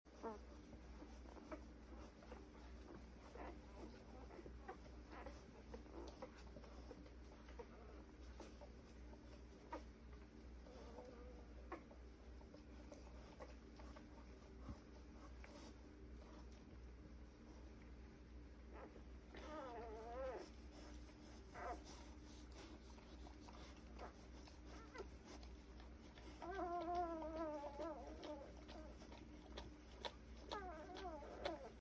The beautiful sound of babies nursing.